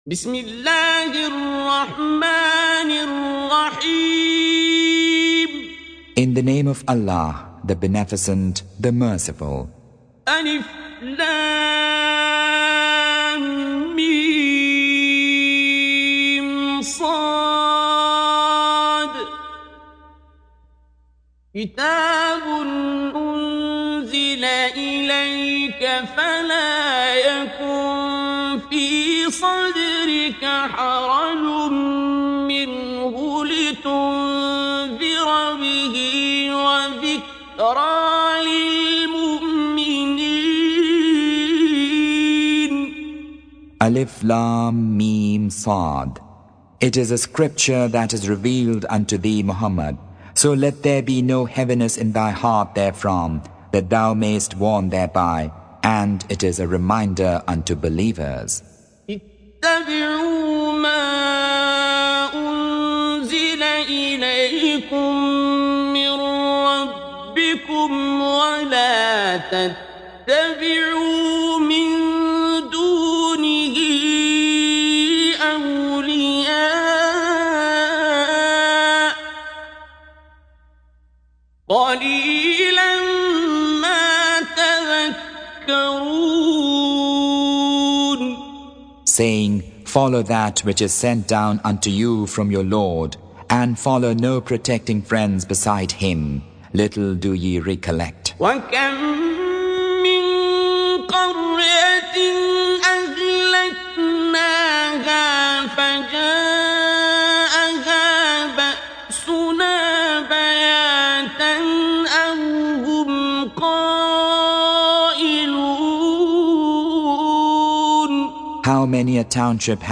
Surah Repeating تكرار السورة Download Surah حمّل السورة Reciting Mutarjamah Translation Audio for 7. Surah Al-A'r�f سورة الأعراف N.B *Surah Includes Al-Basmalah Reciters Sequents تتابع التلاوات Reciters Repeats تكرار التلاوات